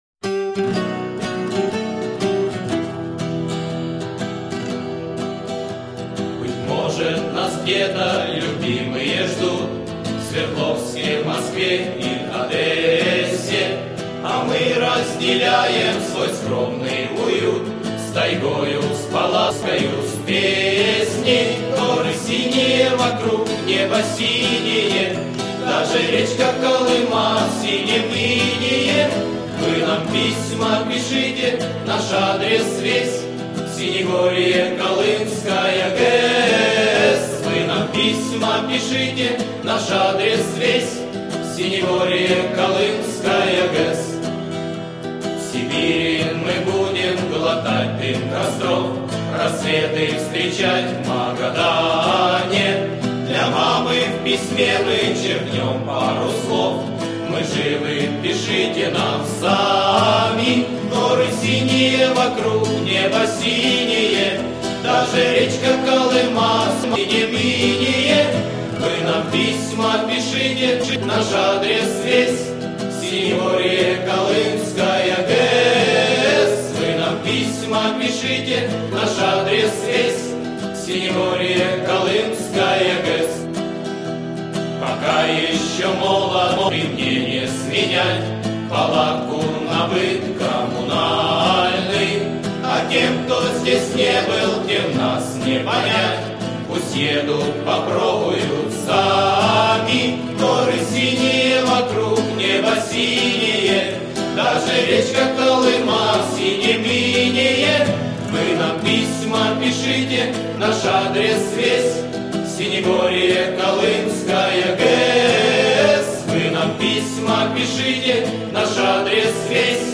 Но мне 1-й вариант тоже очень понравился, практически как тот, что я тогда видел (только голоса мужские).